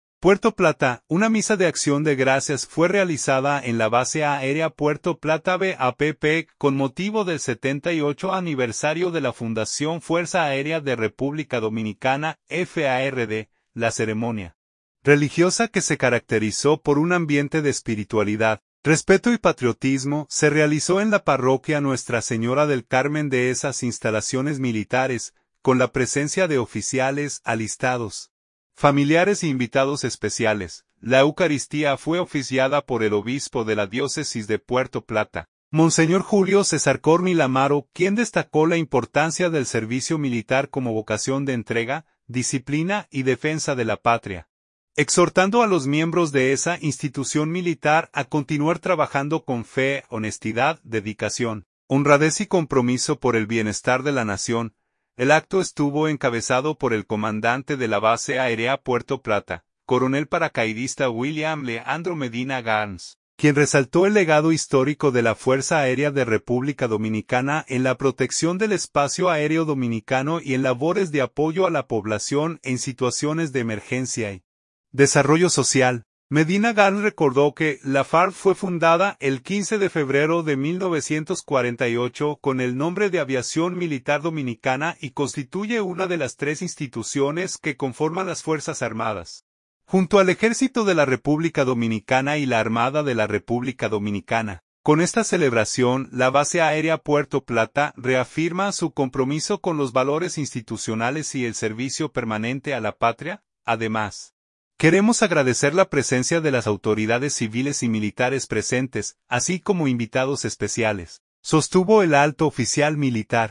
PUERTO PLATA.- Una misa de acción de gracias fue realizada en la Base Aérea Puerto Plata (BAPP) con motivo del 78 aniversario de la fundación Fuerza Aérea de República Dominicana (FARD).
La eucaristía fue oficiada por el obispo de la Diócesis de Puerto Plata, monseñor Julio César Corniel Amaro, quien destacó la importancia del servicio militar como vocación de entrega, disciplina y defensa de la patria, exhortando a los miembros de esa institución militar a continuar trabajando con fe, honestidad, dedicación, honradez y compromiso por el bienestar de la nación.